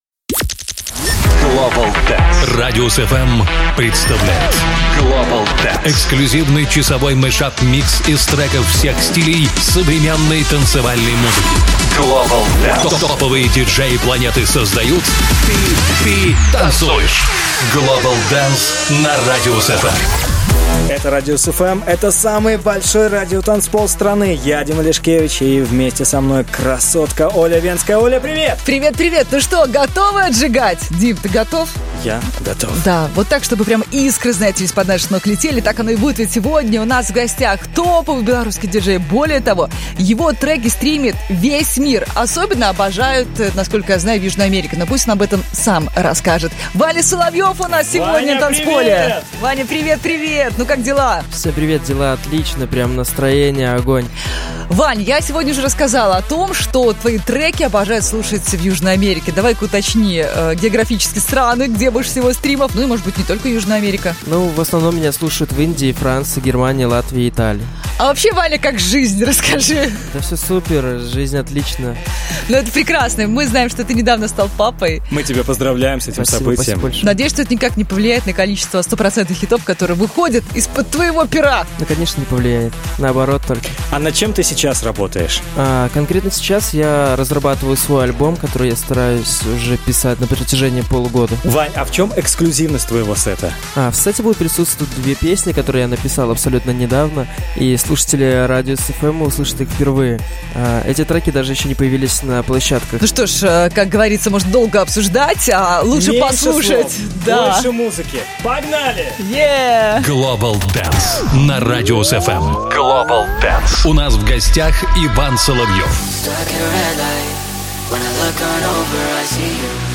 крутой белорусский ди-джей